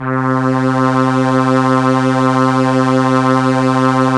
Index of /90_sSampleCDs/Roland LCDP09 Keys of the 60s and 70s 1/STR_ARP Strings/STR_ARP Ensemble